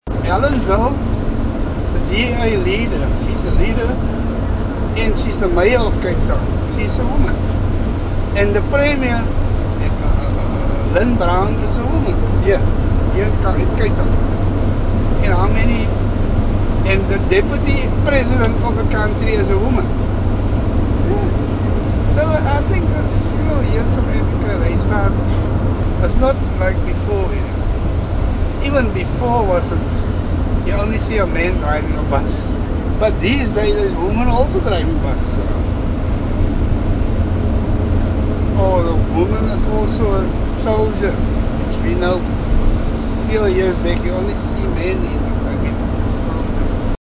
Taxi tales - Cape Town taxi drivers speak about feminism
During their stay, they spoke with taxi drivers about feminism, women in politics and violence against women.